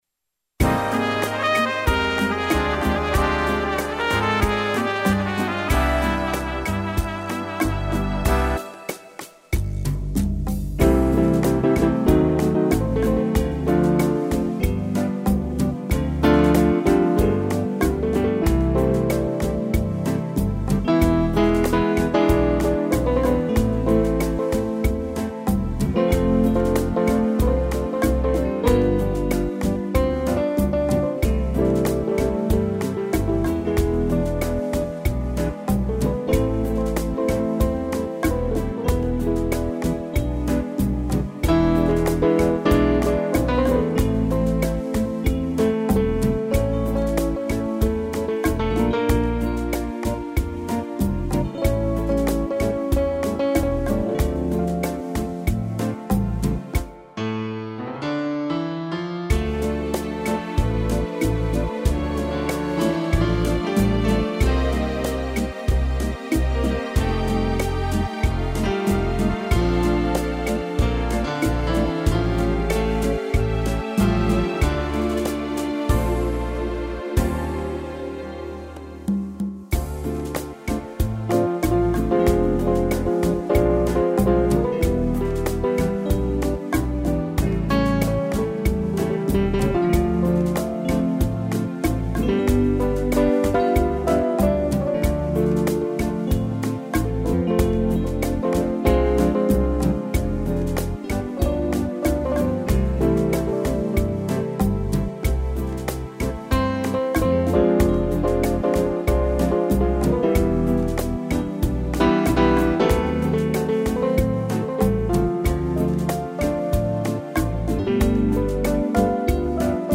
2 pianos